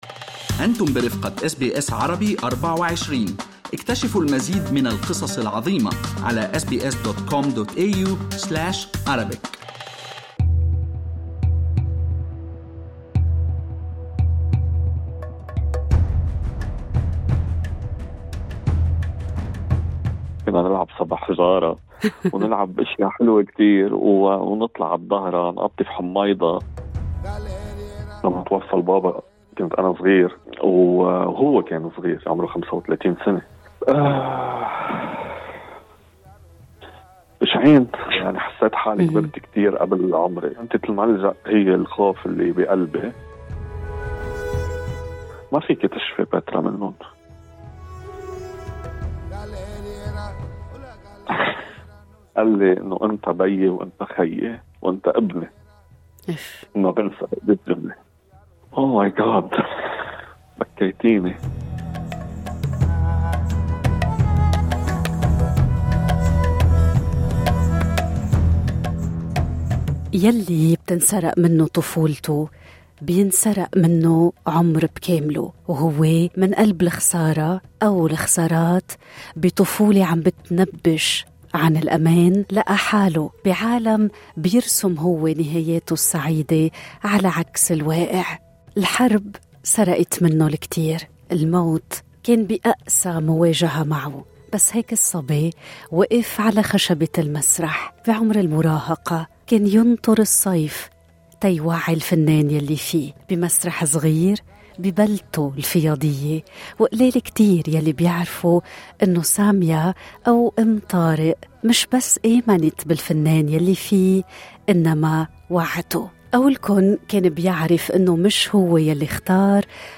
ماذا سيكشف عن 43 سنة من البحث عن ذاته في لقاء اول وحصري من استراليا؟